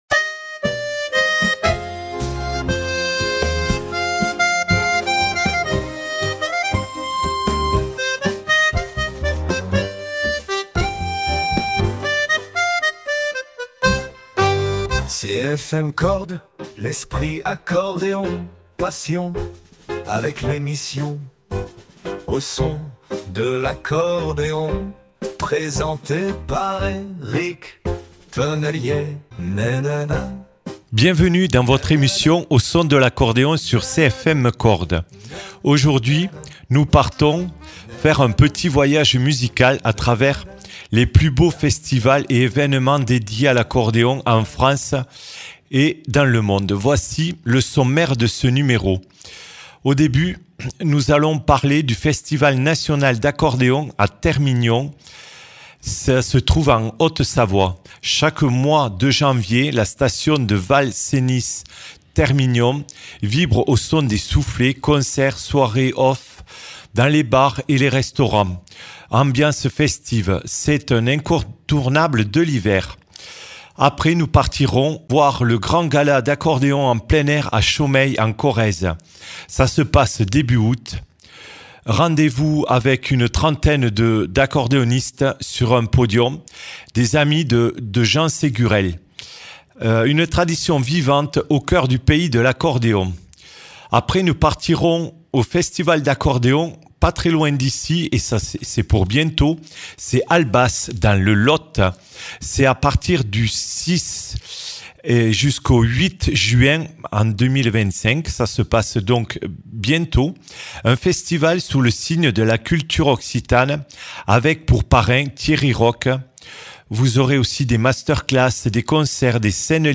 Qu’ils soient en Occitanie ou non, baladons nos oreilles sur des festivals où l’accordéon est à l’honneur. L’occasion de découvrir ou apprécier des artistes qui plus est du monde entier.